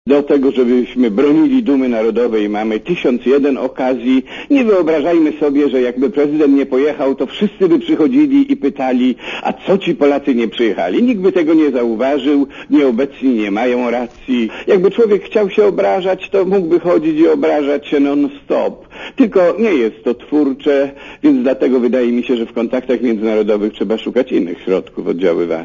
Mówi abp Józef Życiński
Do tego, żebyśmy bronili naszej dumy narodowej, mamy tysiąc jeden okazji - powiedział abp Życiński w Radiu Zet.